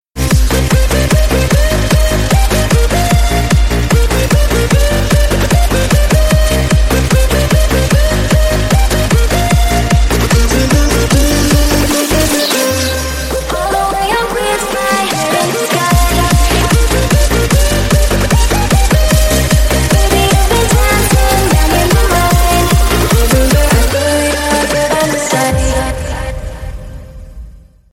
• Качество: 320 kbps, Stereo
Танцевальные
клубные
громкие
ритмичные